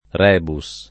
rebus [ r $ bu S ] s. m.